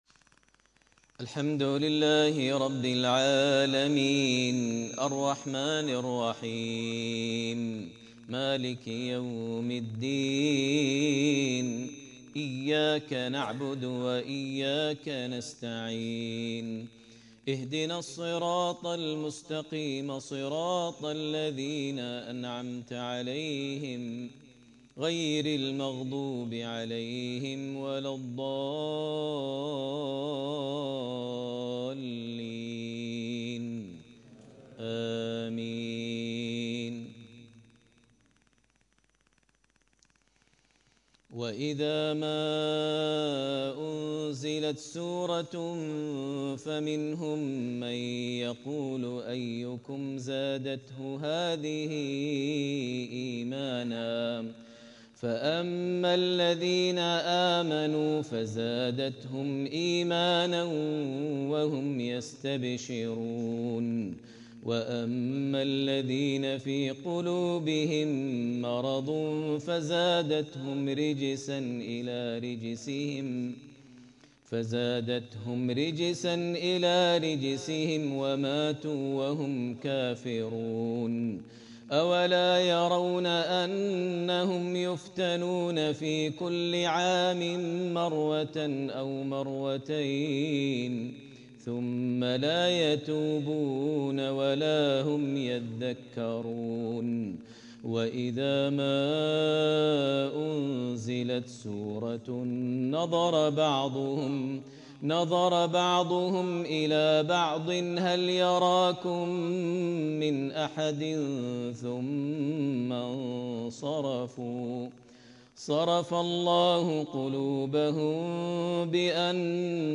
4. زيارة الشيخ د. ماهر المعيقلي إلى مقدونيا الشمالية 1447هـ